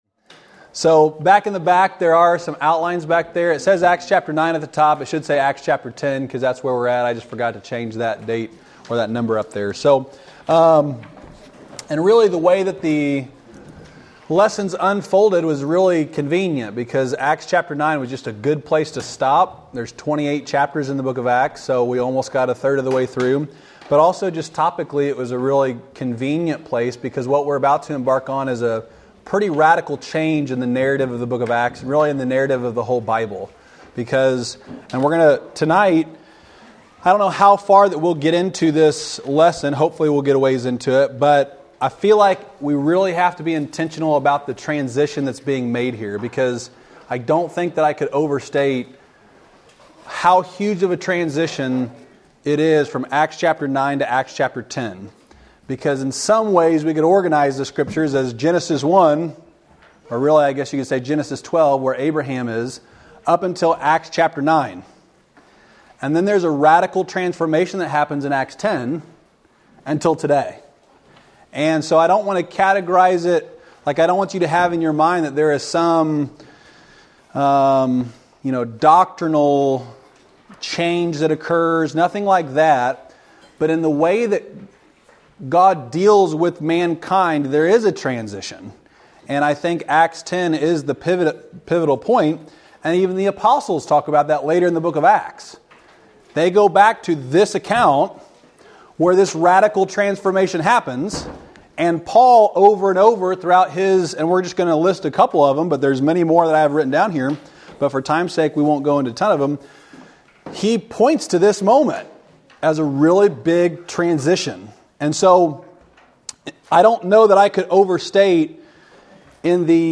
Wednesday night lesson from January 3, 2024 at Old Union Missionary Baptist Church in Bowling Green, Kentucky.